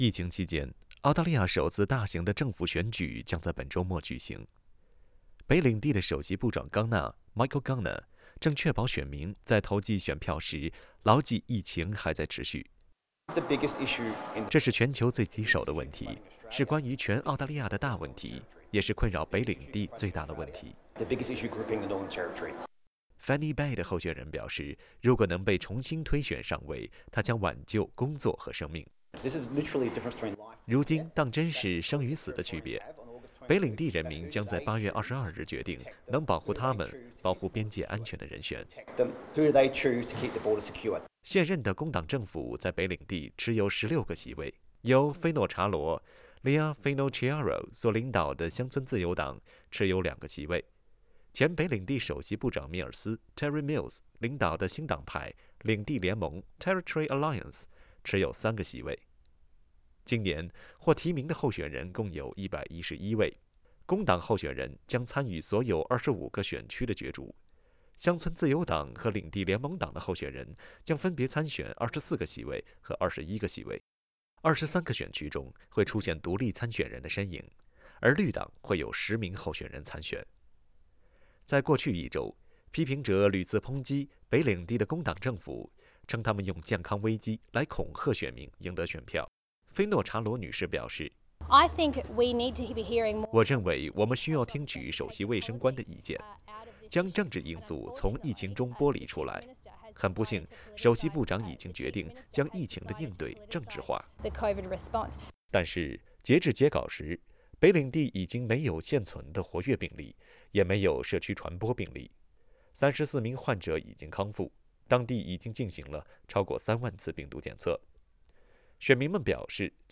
（歡迎點擊圖片音頻，收聽寀訪。）